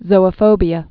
(zōə-fōbē-ə)